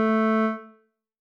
添加三个简单乐器采样包并加载（之后用于替换部分音效）